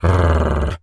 dog_growl_02.wav